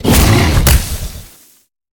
Sfx_creature_snowstalker_flinch_land_01.ogg